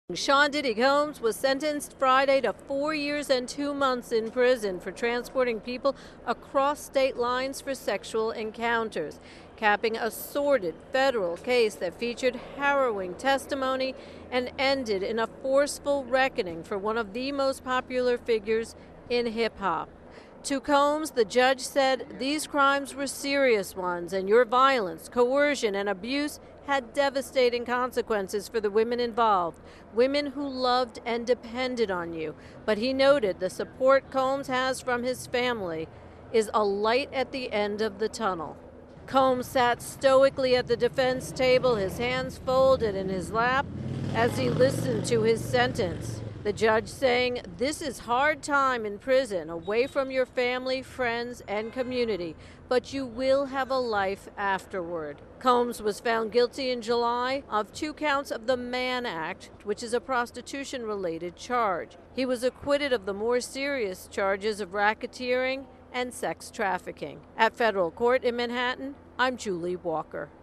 reports on the sentencing of media mogul Sean "Diddy" combs.